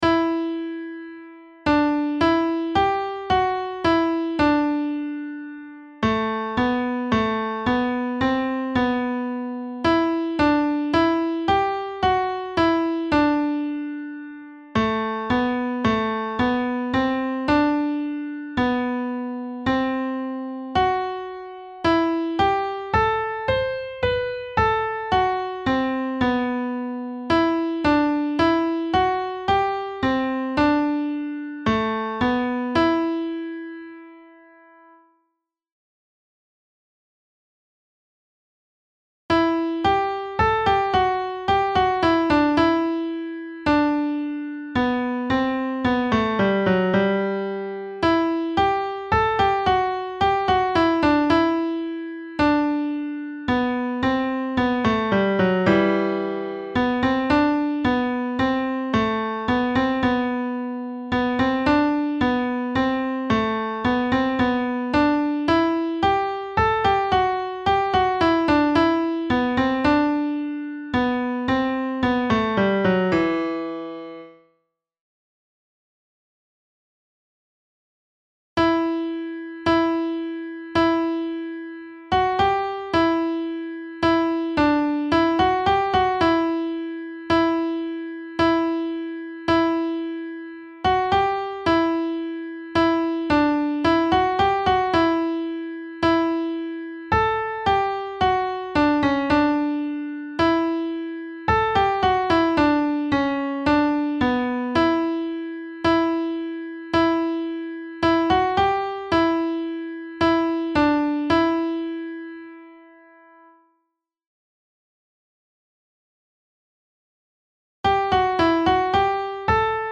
alti-mp3
noel_nouvelet-alt.mp3